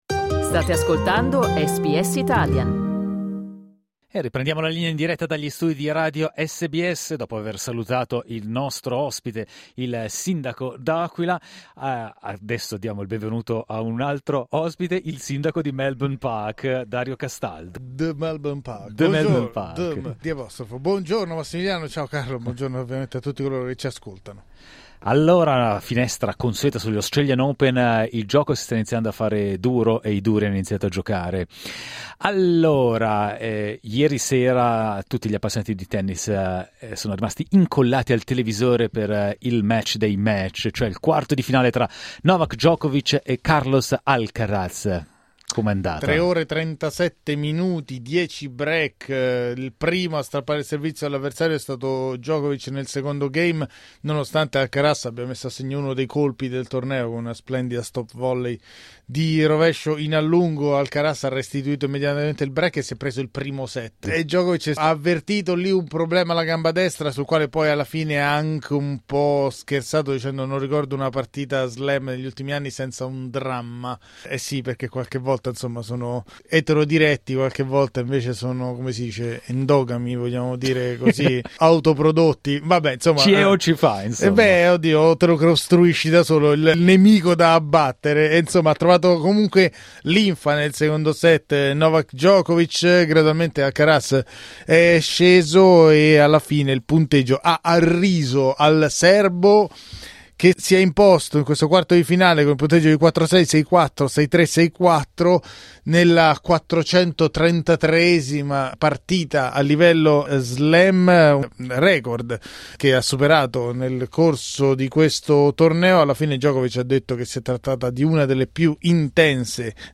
Il 37enne serbo batte in rimonta Carlos Alcaraz e si qualifica per le semifinali degli Australian Open. Ascolta le parole dei protagonisti della decima giornata dell'Happy Slam 2025.